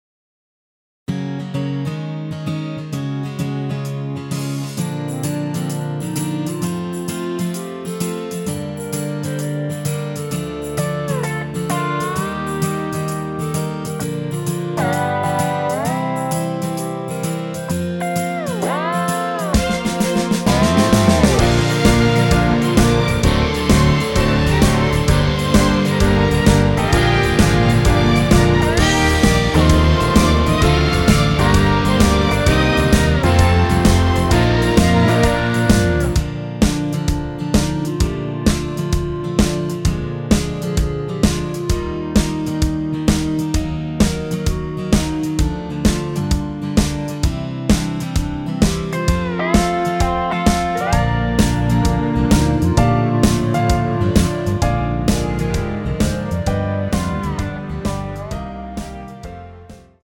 전주 없는 곡이라 전주 2마디 만들어 놓았습니다.
엔딩이 페이드 아웃이라라이브 하시기 편하게 엔딩을 만들어 놓았습니다.
Bb
노래방에서 노래를 부르실때 노래 부분에 가이드 멜로디가 따라 나와서
앞부분30초, 뒷부분30초씩 편집해서 올려 드리고 있습니다.
중간에 음이 끈어지고 다시 나오는 이유는